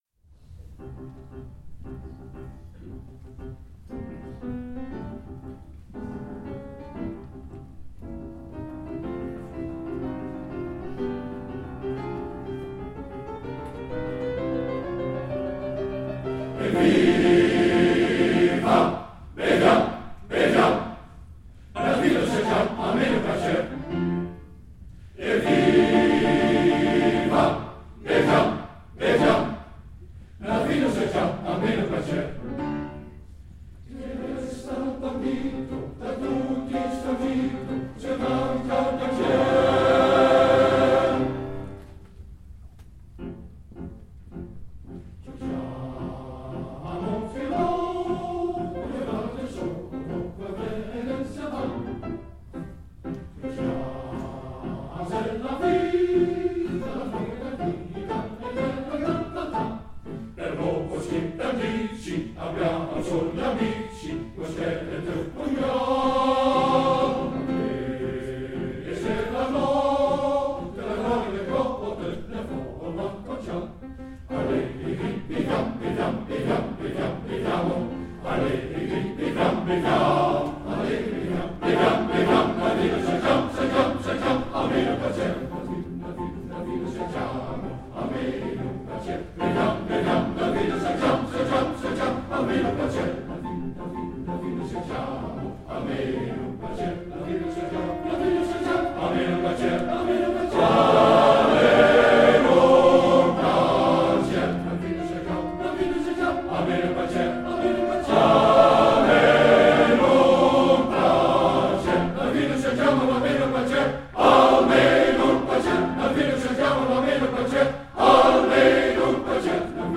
Hieronder treft u een aantal muziekfragmenten aan van Mannenkoor Lambardi.